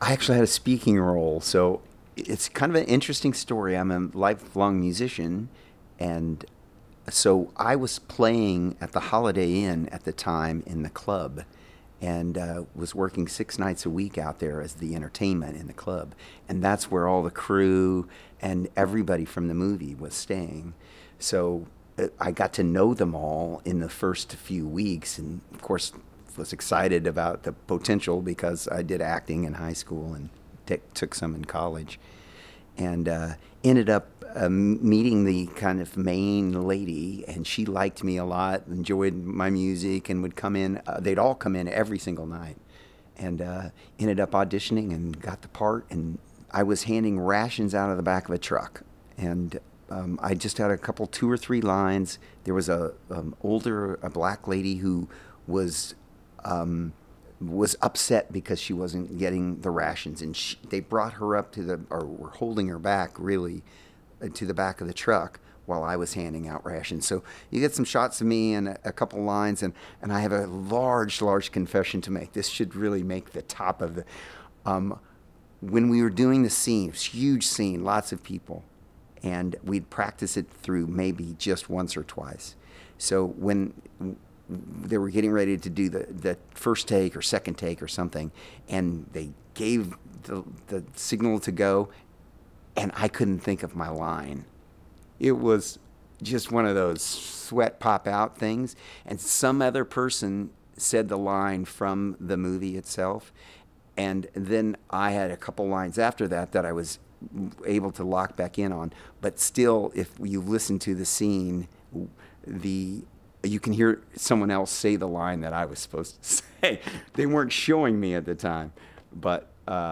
The interview was conducted at the Watkins Museum of History on June 28, 2012.
Oral History